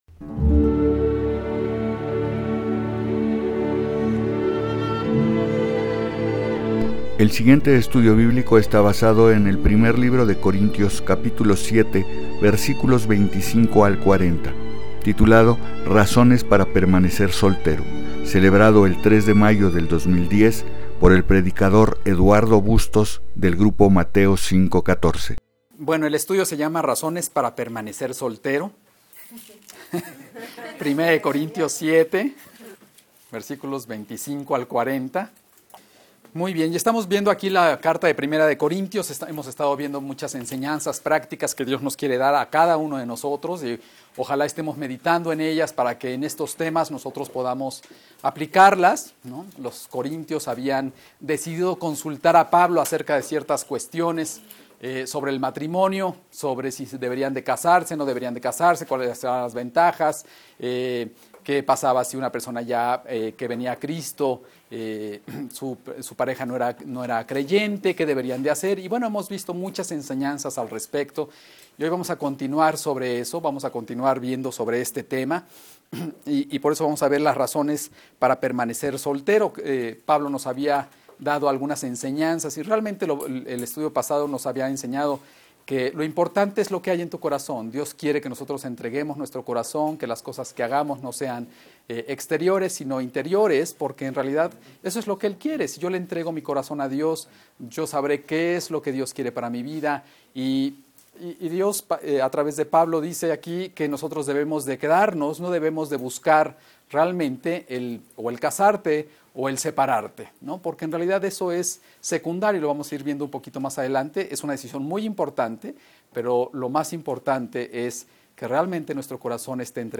2010 Razones para Permanecer Soltero Preacher